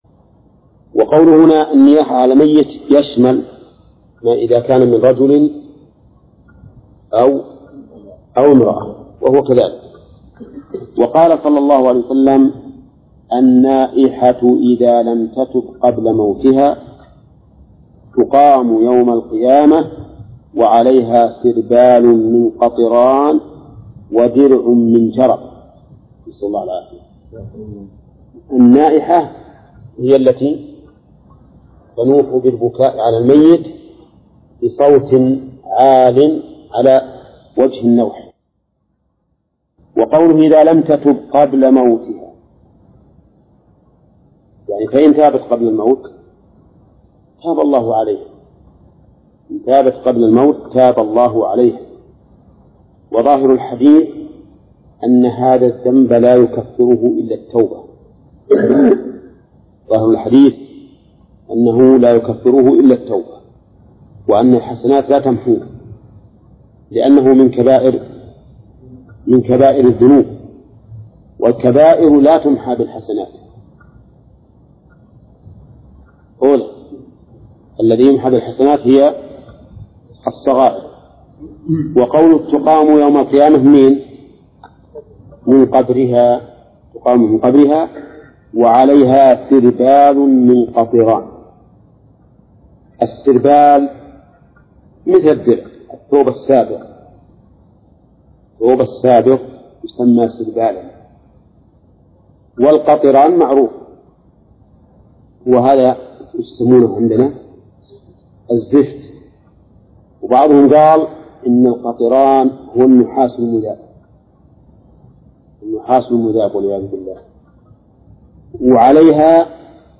درس (30) / المجلد الثاني : من صفحة: (25)، قوله: (وقال: النائحة إذا لم تتب..)، إلى صفحة: (44)، قوله: (باب قوله تعالى: {ومن الناس ..}).